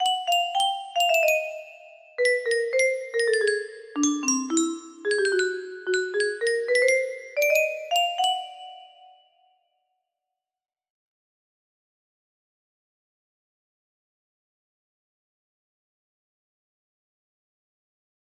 rift leap music box melody
But, I wanted to test the higher notes.
:) sounds nice :)